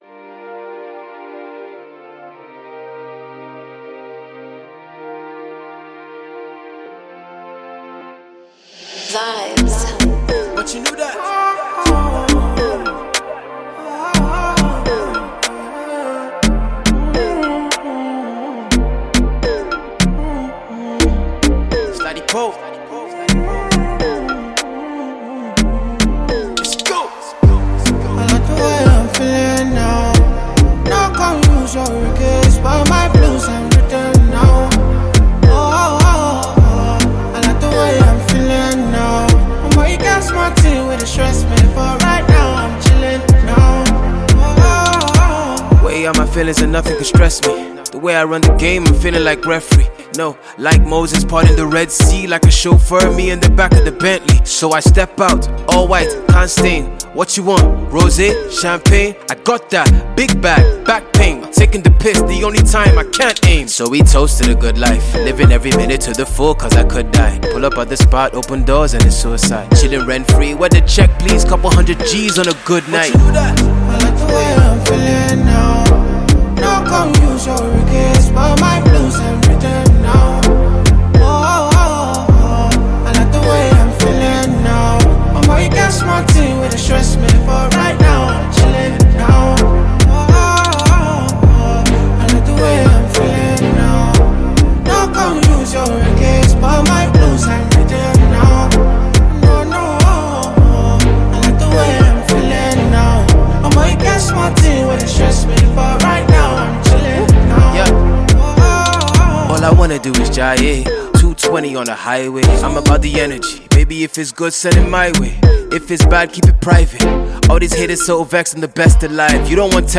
party thriller
super-chilled tune